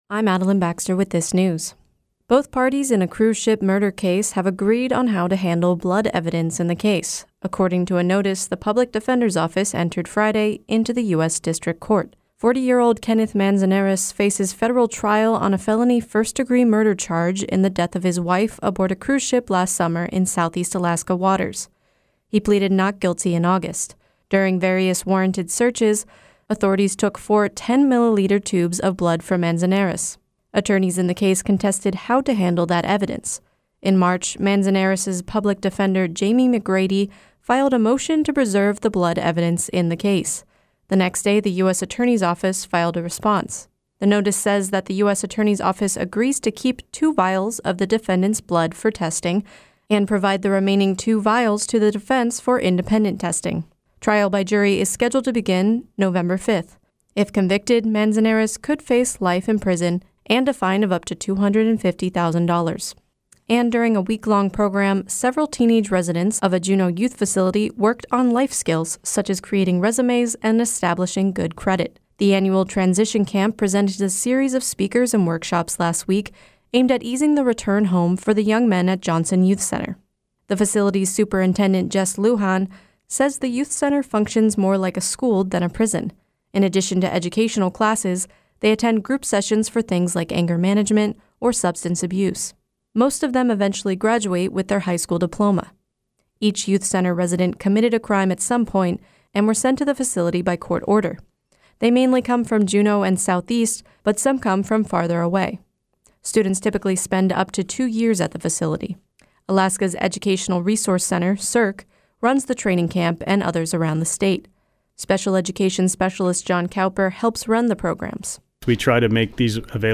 Newscast — Monday, April 9, 2018